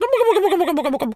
pgs/Assets/Audio/Animal_Impersonations/turkey_ostrich_gobble_06.wav at 7452e70b8c5ad2f7daae623e1a952eb18c9caab4
turkey_ostrich_gobble_06.wav